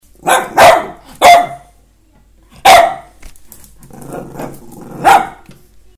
J'aboie
loulouaboie1.mp3